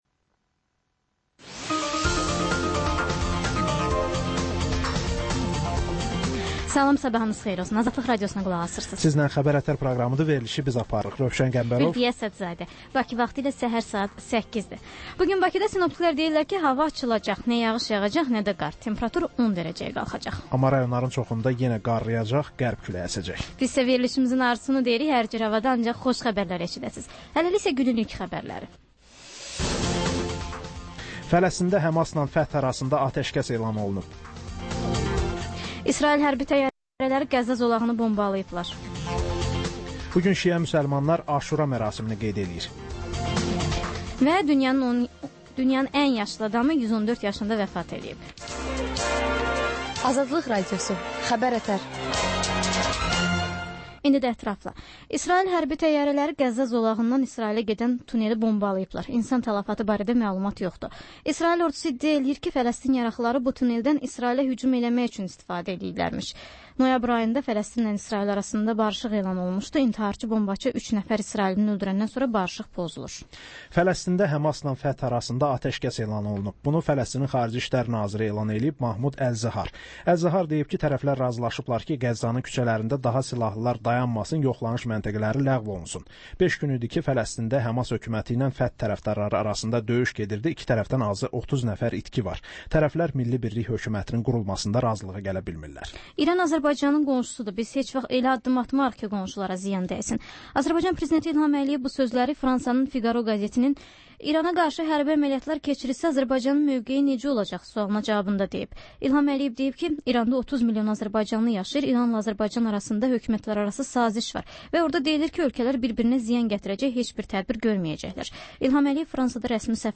Səhər-səhər, Xəbər-ətər: xəbərlər, reportajlar, müsahibələr. Hadisələrin müzakirəsi, təhlillər, xüsusi reportajlar.